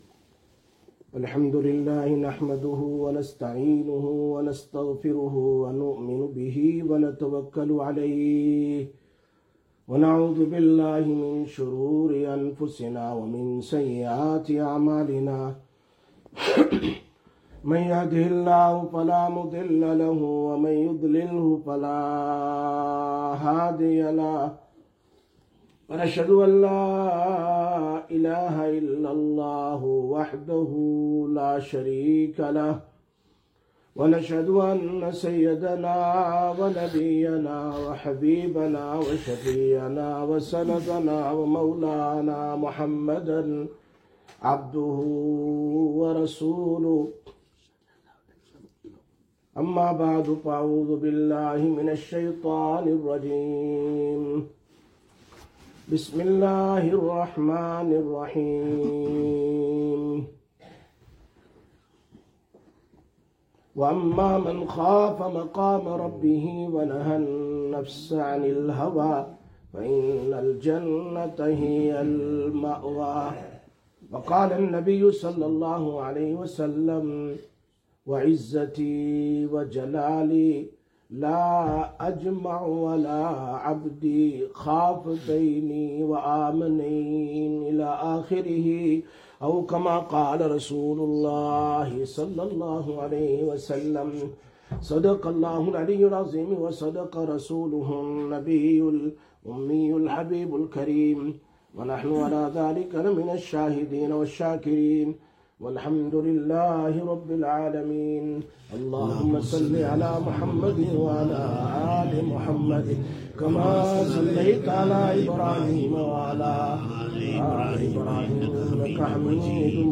02-05-25 Masjid Quba Jummah Bayaan Fear and Hope
02/05/2025 Jumma Bayan, Masjid Quba